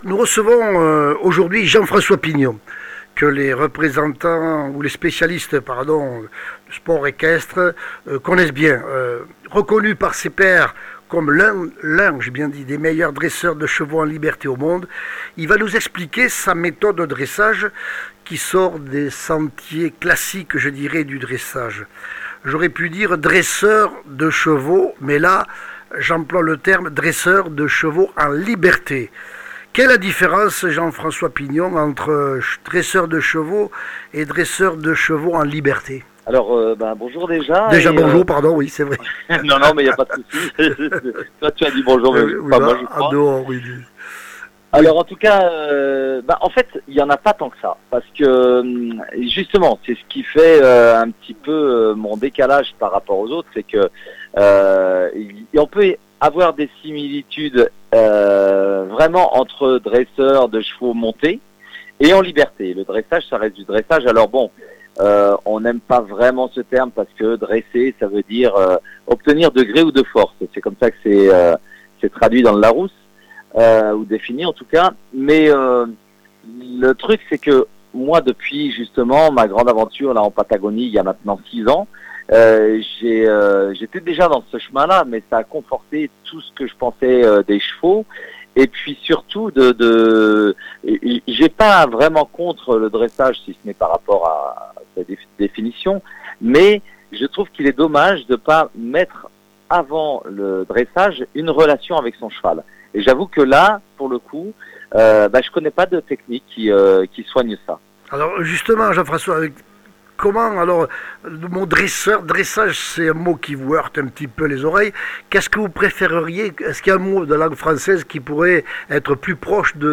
Comminges Interviews du 01 juil.